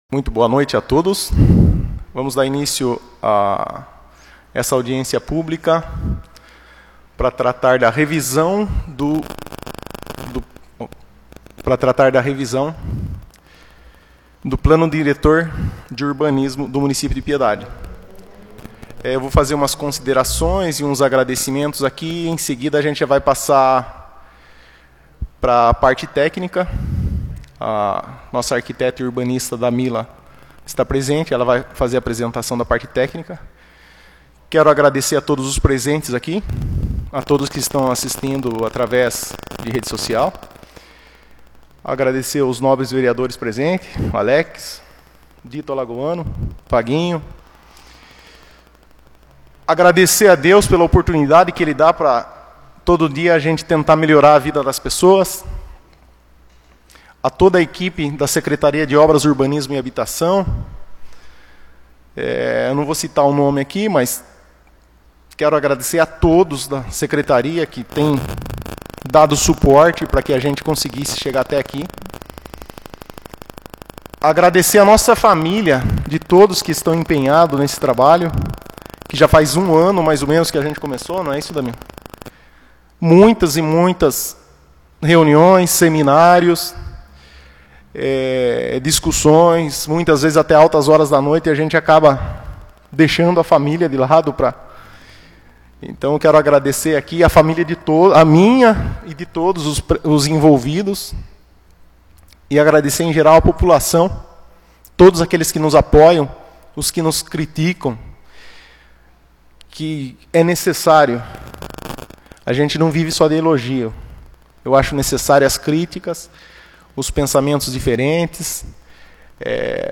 Audiência Pública do Plano Diretor 2/10/2020